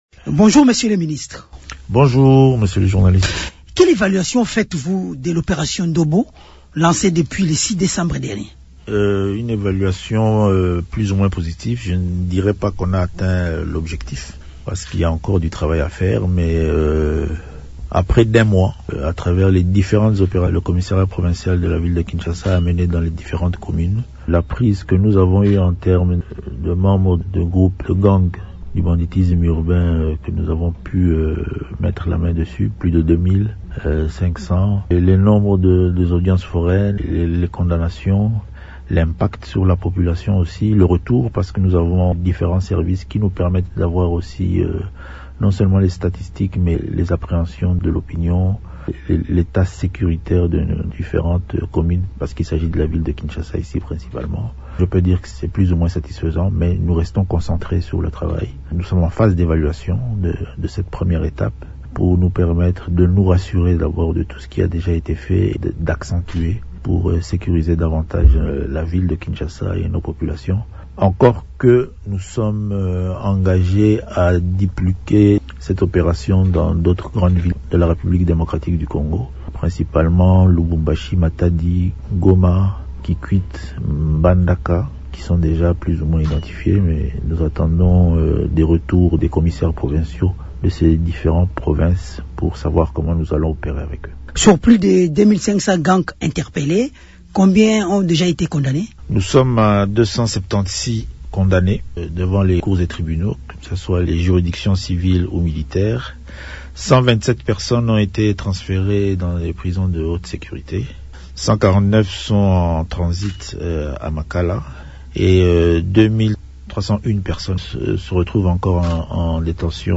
Le vice-Premier ministre et ministre de l’Intérieur, Jacquemain Shabani a livré ces chiffres, ce mardi, dans un entretien accordé à Radio Okapi.